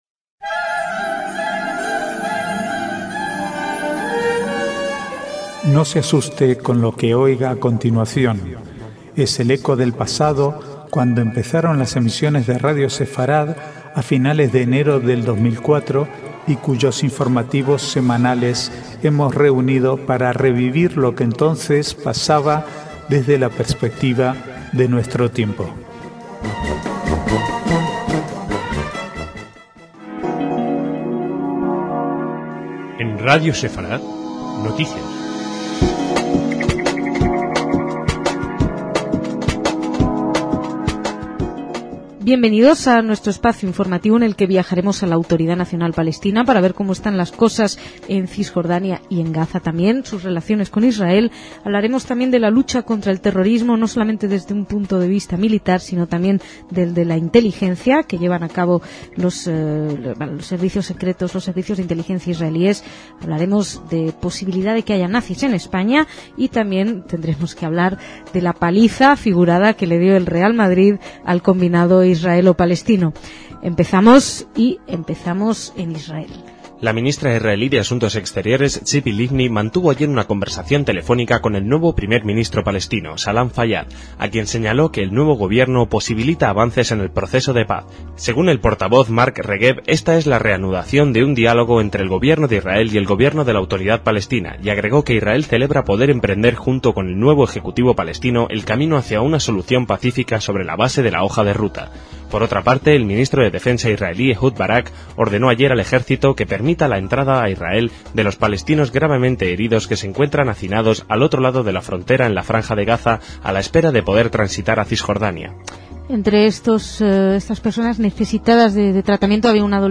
Archivo de noticias del 21 al 26/6/2007